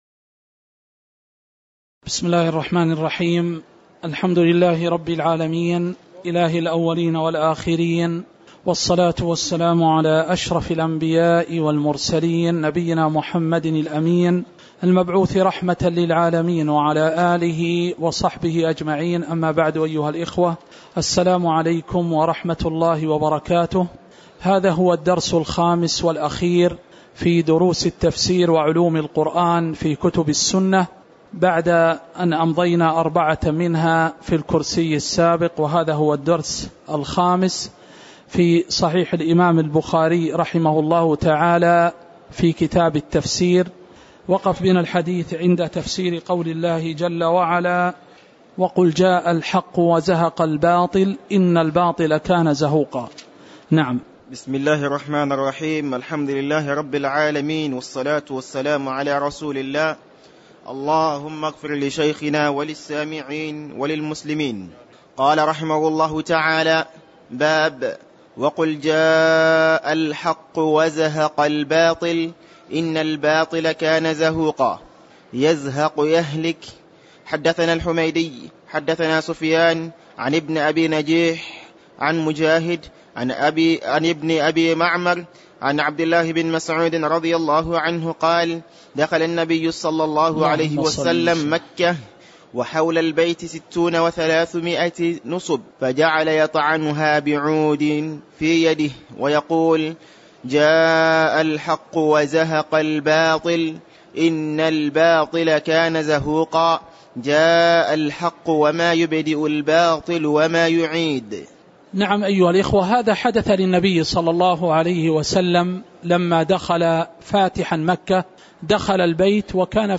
تاريخ النشر ٩ شوال ١٤٣٩ هـ المكان: المسجد النبوي الشيخ